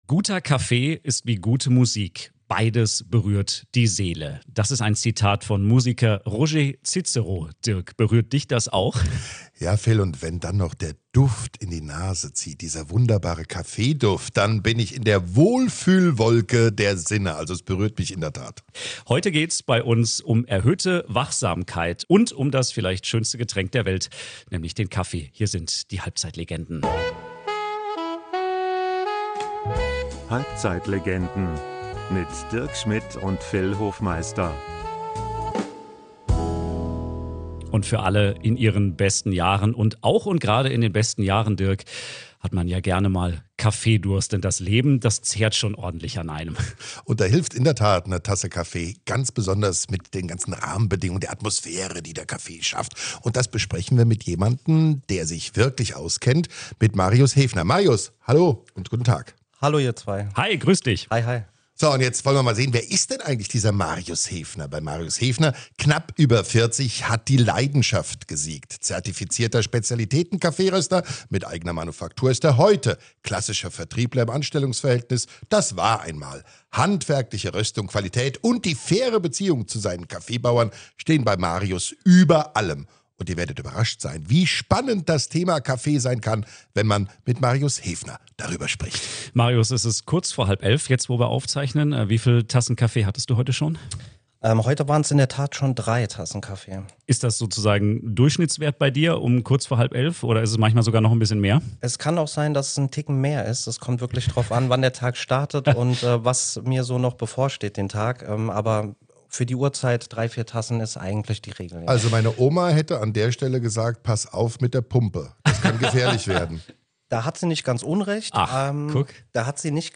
Außerdem klären wir, ob teure Maschinen und Mühlen ihr Geld wert sind - oder ob es auch einfacher und günstiger geht. Und räumen auf mit den größten Mythen über Kaffee. Ein Gespräch über Genussmittel, Gesundheitskick und Gewohnheiten - mit Wachmacher-Effekt und einem doppelten Schuss Humor!